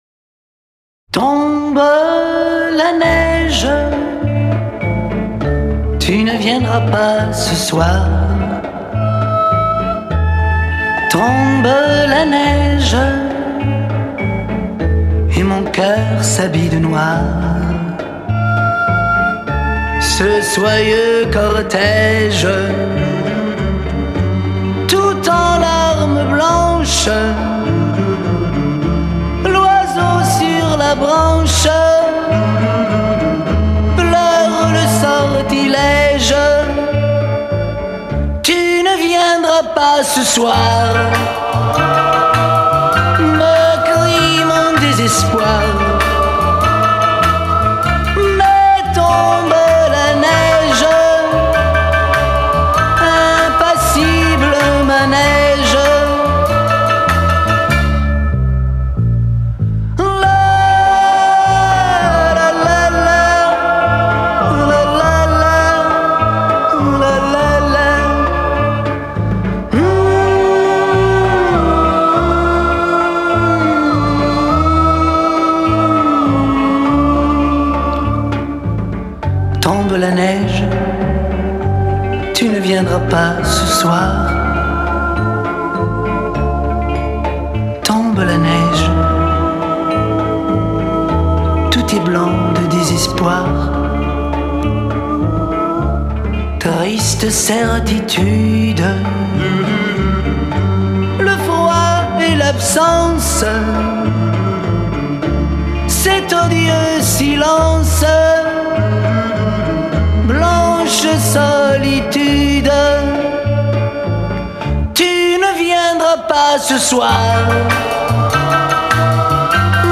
Genre:Pop
Style:Chanson